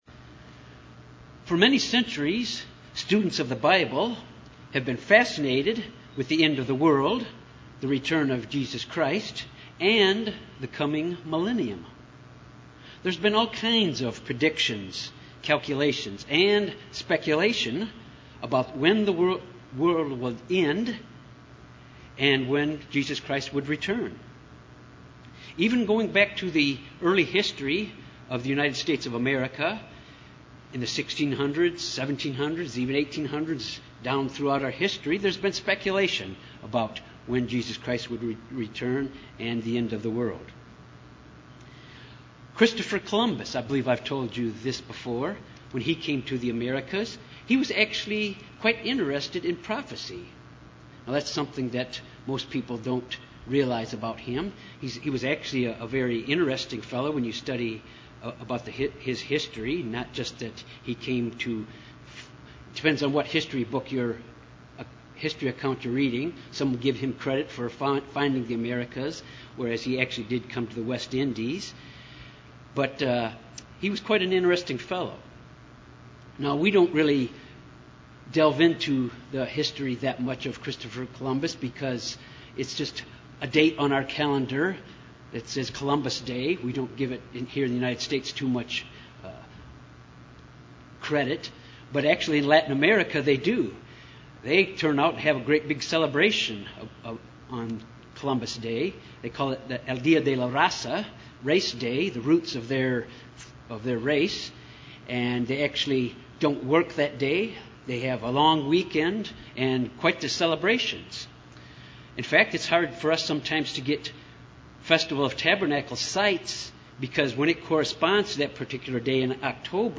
God has a plan for mankind and prophecy shows us details about God’s plan which will stand. This sermon deals with the importance of prophecy found in the Bible as it relates to current events in this year 2014.